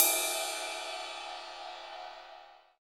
CYM RIDE305R.wav